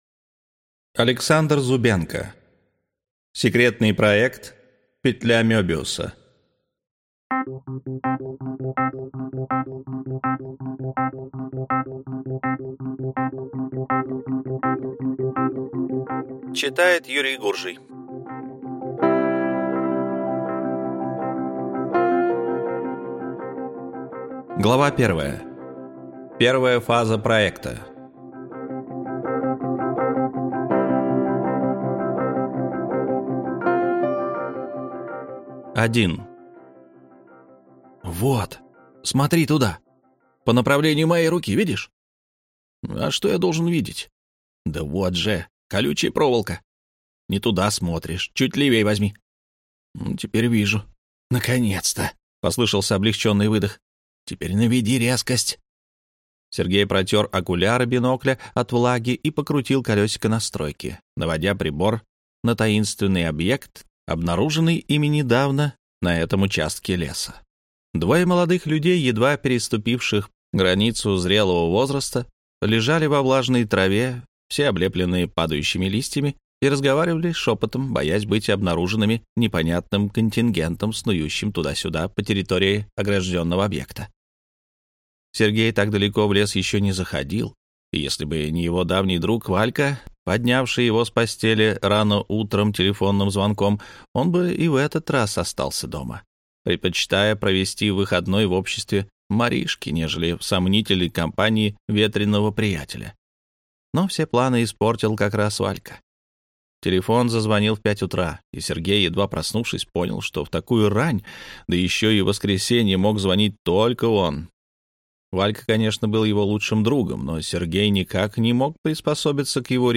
Аудиокнига Секретный проект «Петля Мёбиуса» | Библиотека аудиокниг